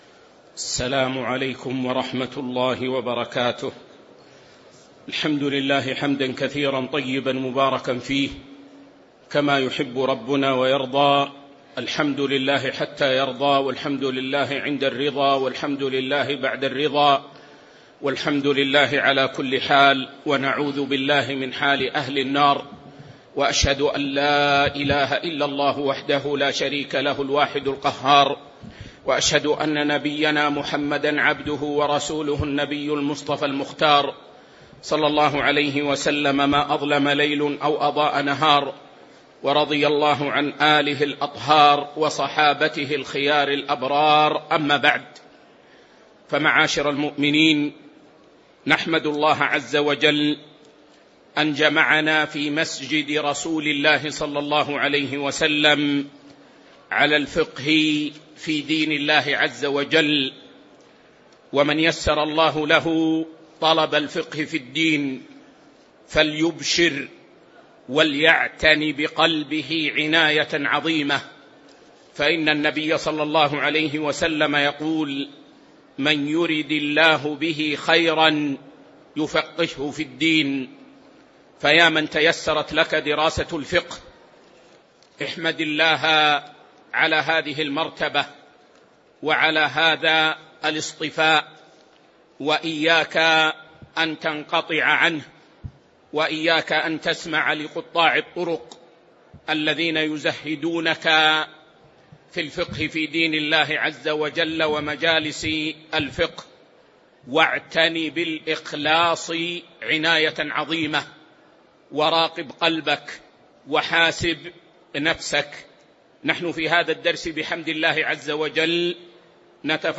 تاريخ النشر ٢٦ جمادى الأولى ١٤٤٦ هـ المكان: المسجد النبوي الشيخ